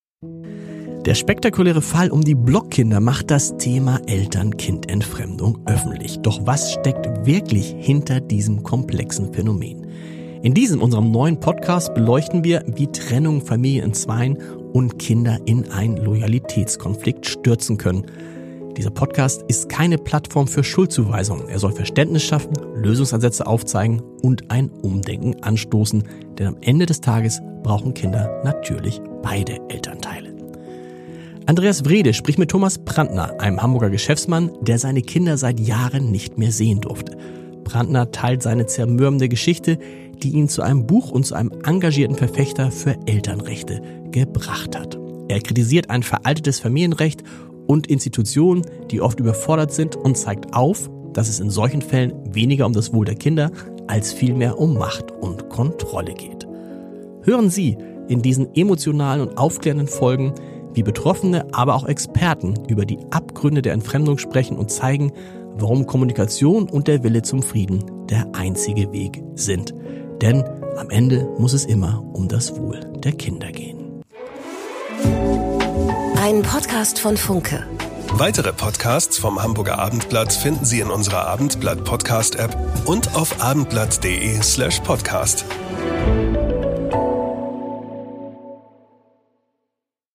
spricht mit Betroffenen und Experten über einen unterschätzten Konflikt.